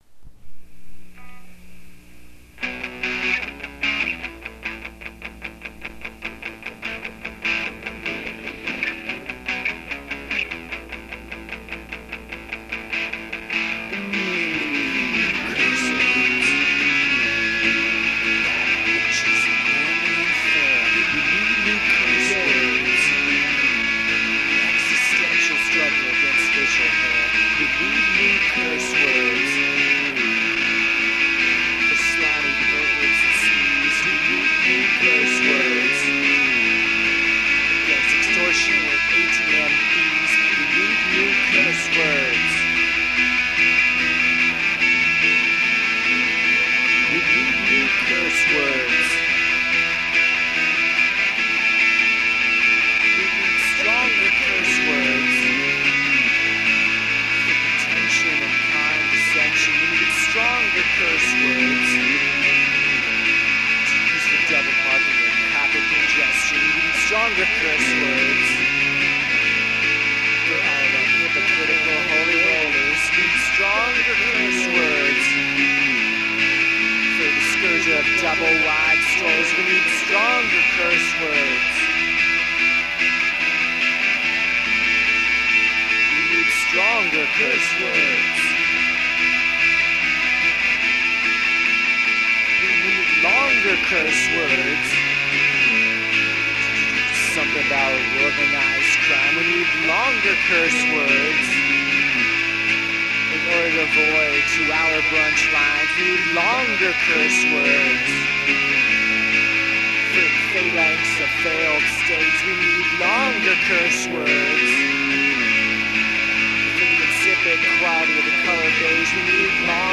(Four-Track Demo)